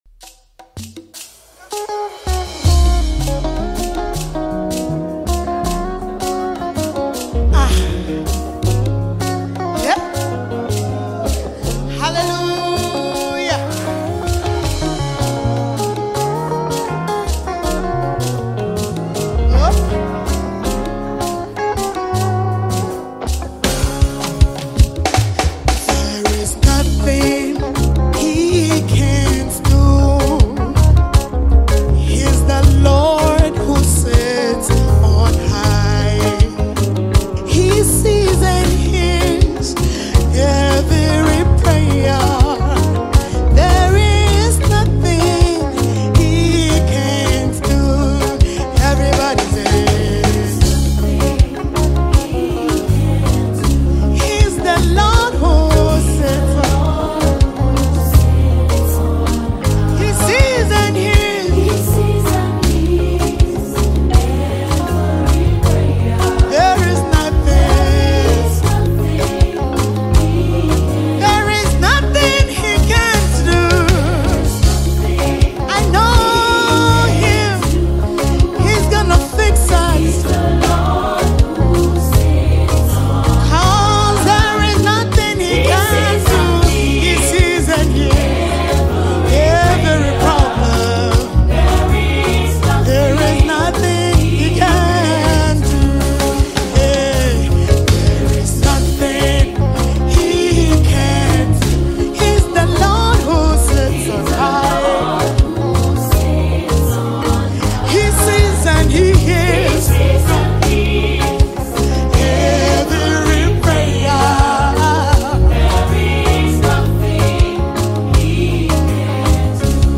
gospel soloist
incredible vocals
soul-lifting and fascinating single